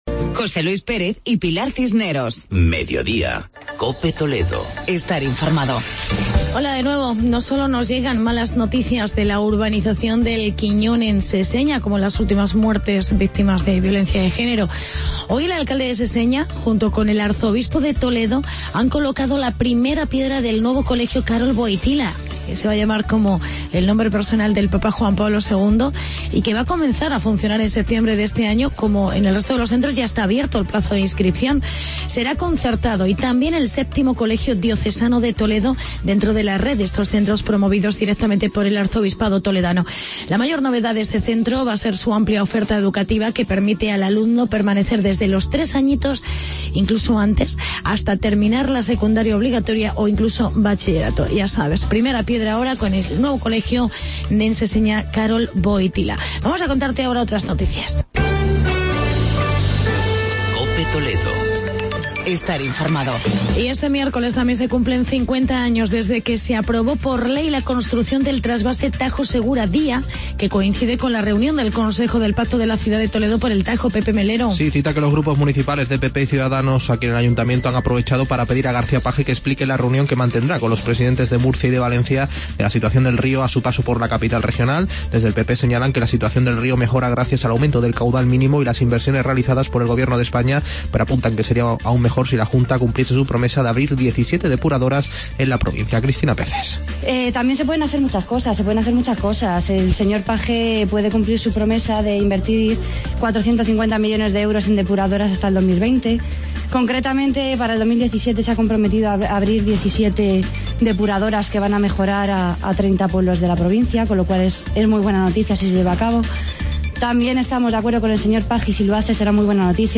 Actualidad y entrevista con Jaime Ramos, alcalde de Talavera de la Reina.